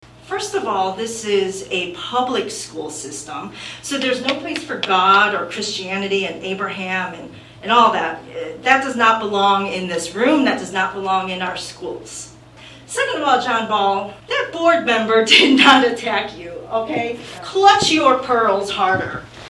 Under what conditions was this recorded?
Tensions rose between the public during Wednesday’s USD 383 Manhattan-Ogden school board meeting.